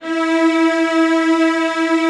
CELLOS F#4-L.wav